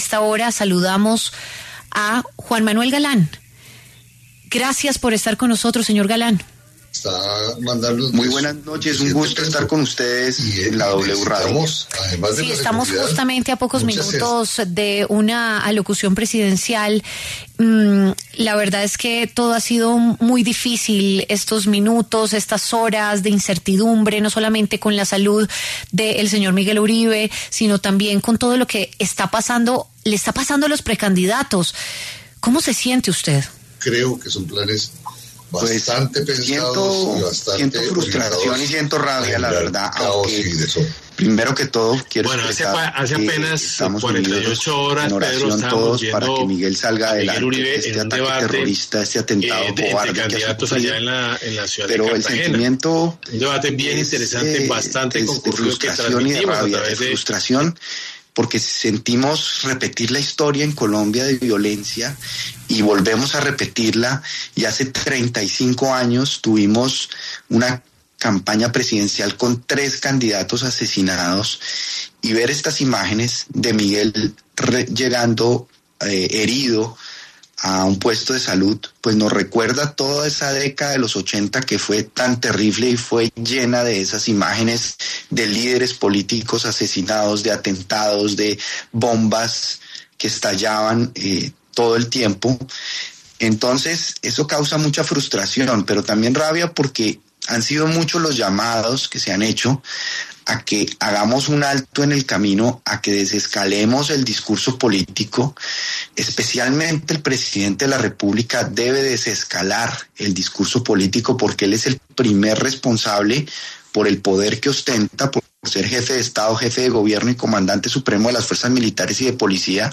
El exsenador Juan Manuel Galán habló con W Radio sobre el atentado que sufrió el precandidato presidencial Miguel Uribe Turbay, del Centro Democrático.